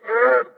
1 channel
Boonga_voice_damage.mp3